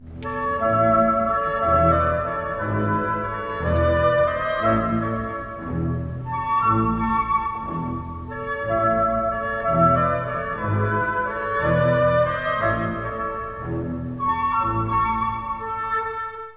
↑しばらくすると、木管による民謡旋律が現れます。
南国情緒豊かです！